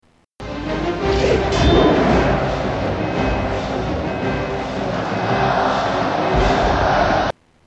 Crowd Noise